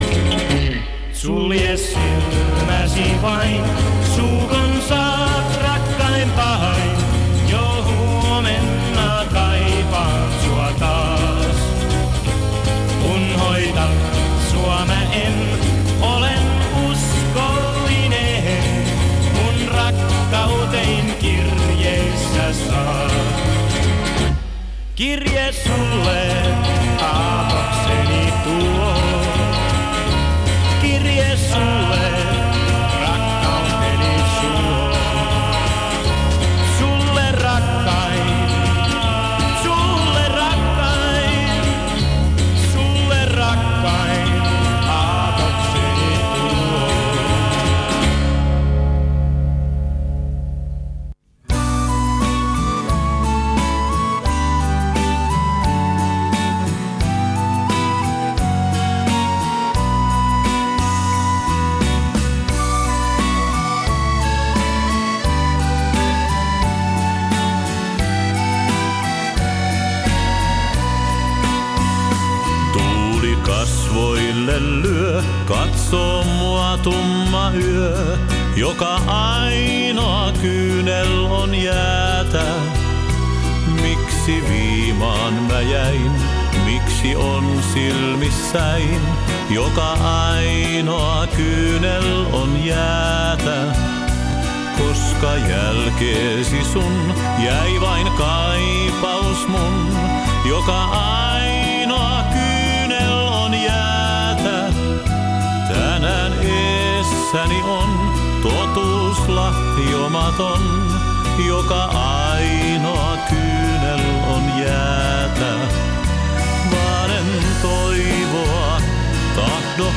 Финская станция Radio Pooki. Спокойная приятная музыка, напоминает советскую эстраду и советские ВИА.